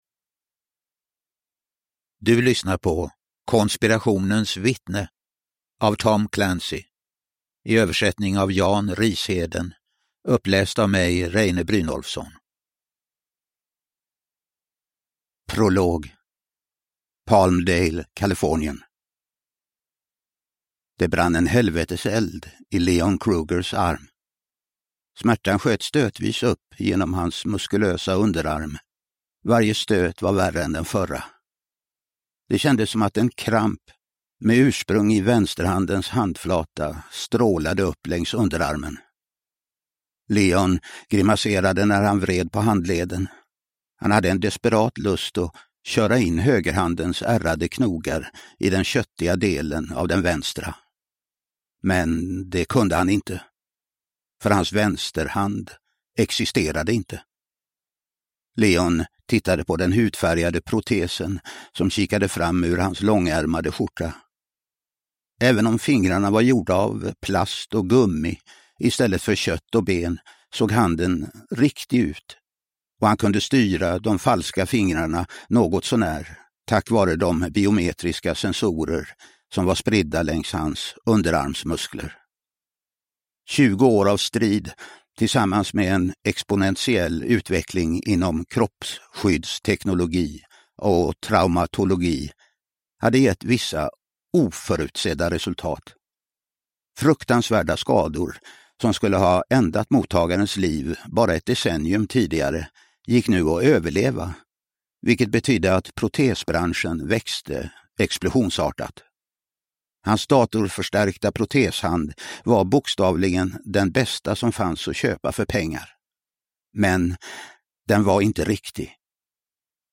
Konspirationens vittne – Ljudbok
Uppläsare: Reine Brynolfsson